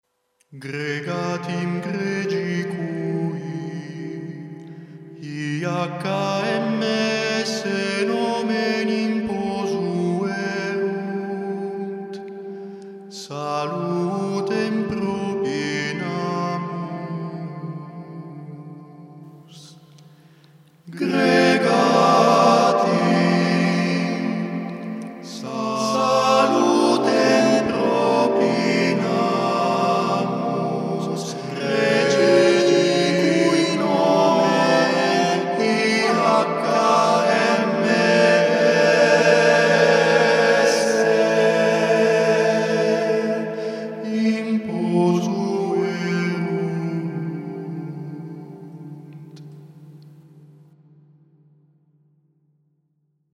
Gregoriana